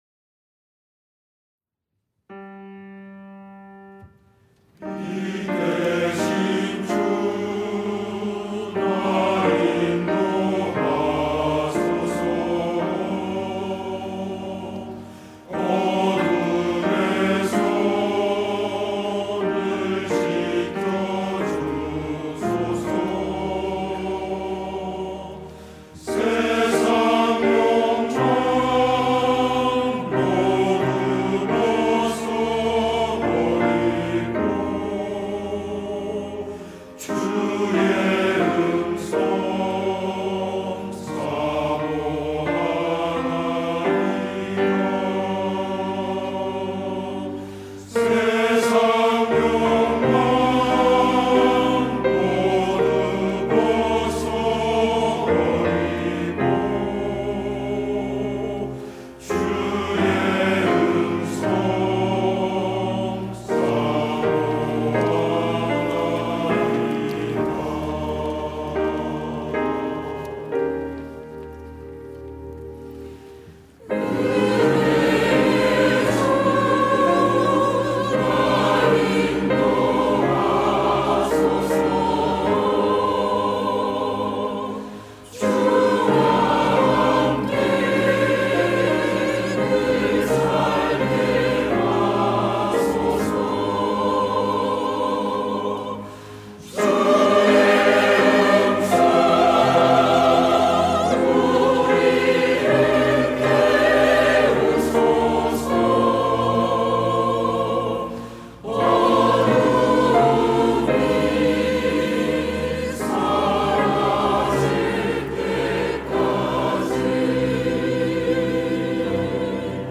시온(주일1부) - 빛 되신 주님
찬양대